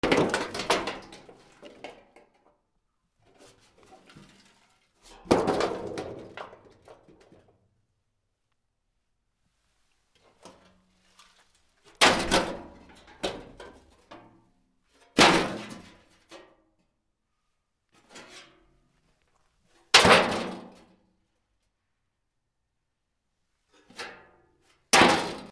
薄い鉄板 衝撃
『ガタン』